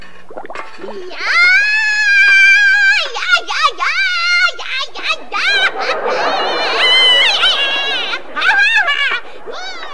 Risada Bruxa Baratuxa
Risada maligna da personagem Bruxa Baratuxa (Maria Antonieta de las Nieves) no Chapolin. Mais precisamente do episódio da simples camponesa de nobre coração que vai todos os dias ao bosque recolher lenha.
risada-bruxa-baratuxa.mp3